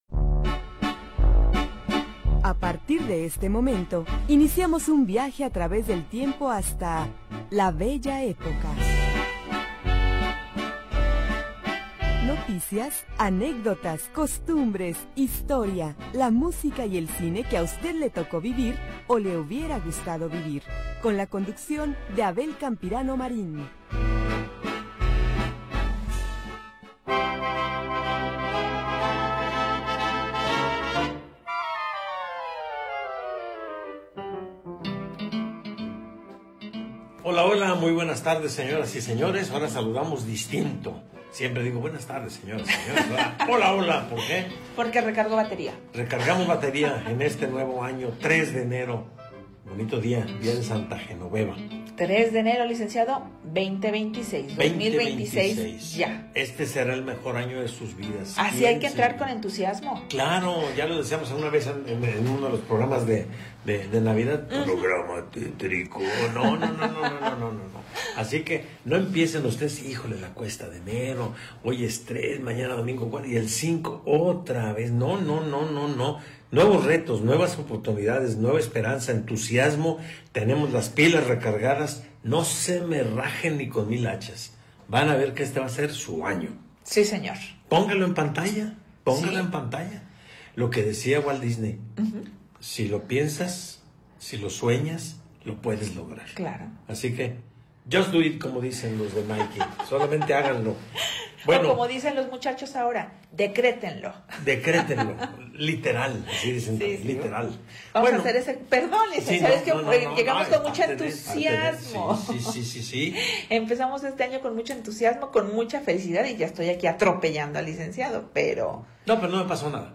Anécdotas, comentarios, música y noticias de ayer y hoy